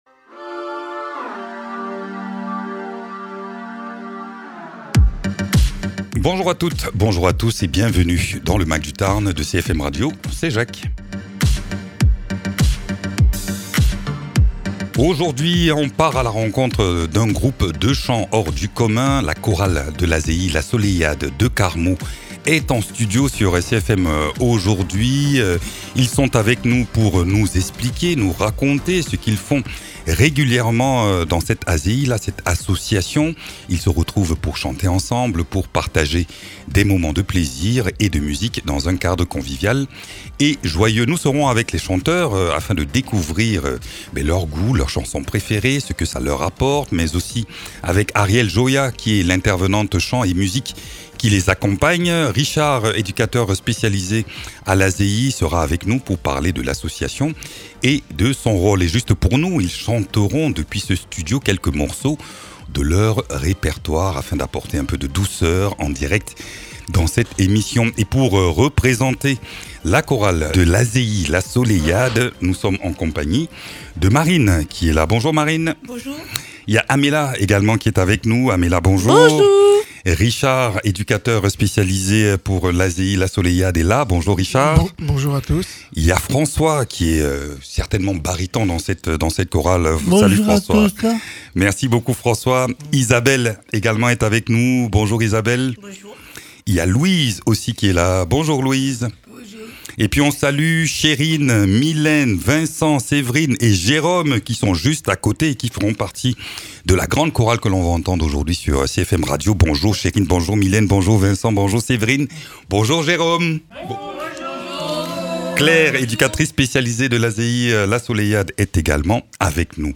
À Carmaux, la chorale de l’ASEI La Soleillade réunit des personnes en situation de handicap autour du chant, accompagnées par leurs éducateurs et une intervenante musicale. Une aventure collective faite de voix, d’émotions et de moments de joie partagés, jusque dans le studio de CFM.
Interviews